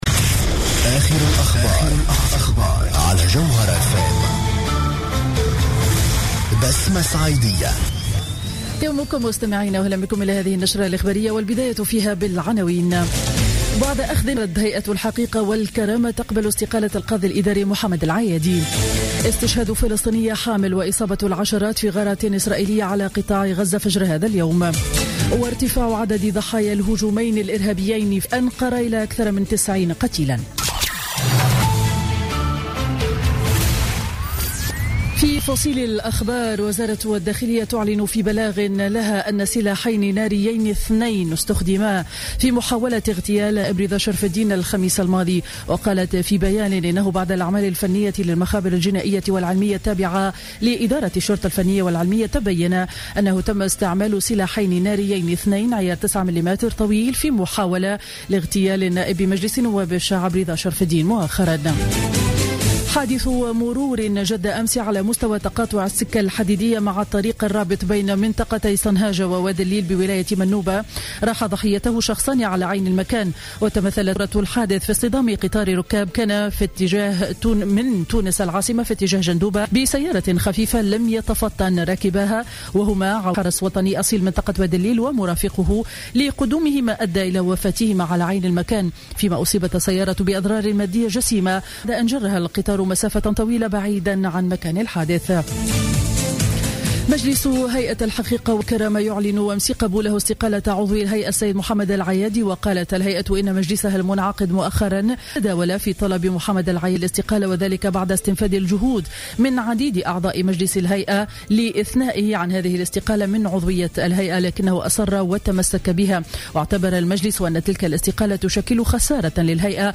نشرة أخبار السابعة صباحا ليوم الاحد 11 أكتوبر 2015